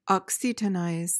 PRONUNCIATION:
(OK-si-tuh-nyz)